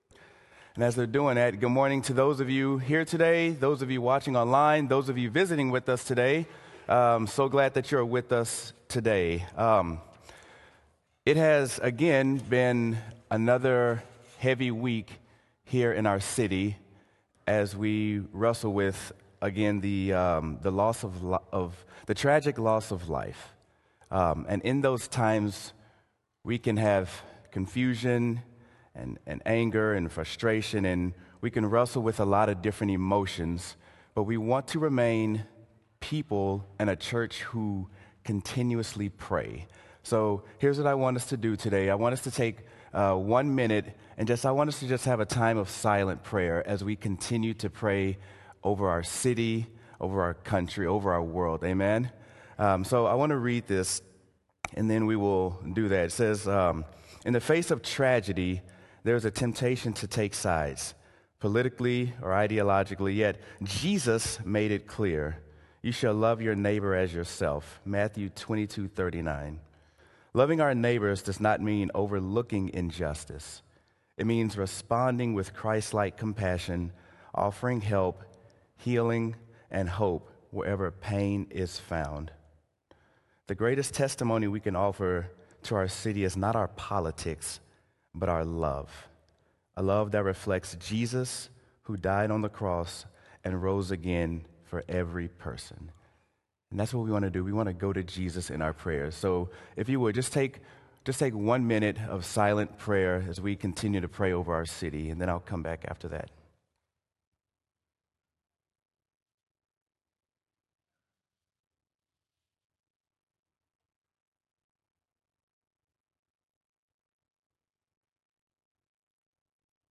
Sermon: What To Do When You Don’t Know What To Do
sermon-what-to-do-when-you-dont-know-what-to-do.m4a